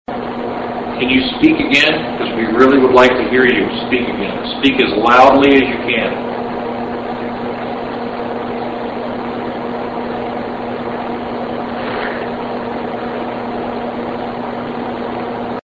EVP Evidence
msgevp_hard1.mp3